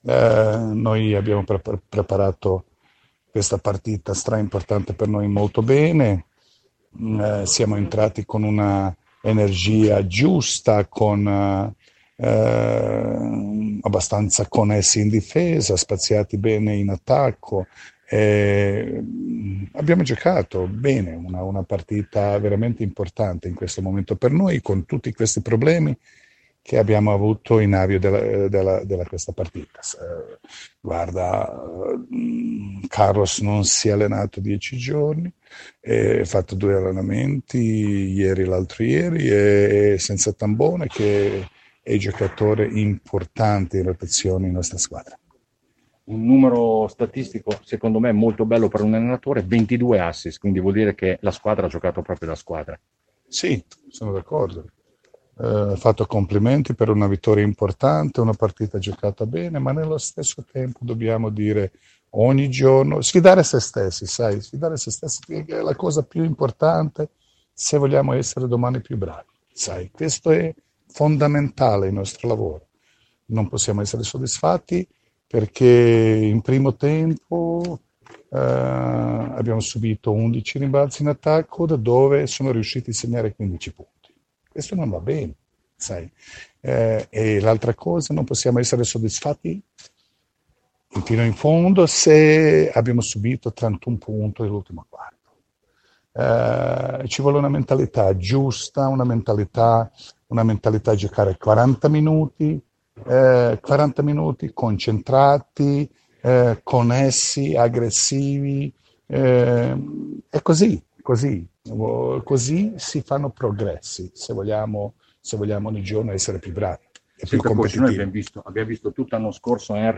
Alla Vitrifrigo Arena i ragazzi di Coach Repesa, nella 17^ giornata di Serie A, battono l’Acqua San Bernardo Cantù per 107-83. Le interviste a Jasmin Repesa e Cesare Pancotto.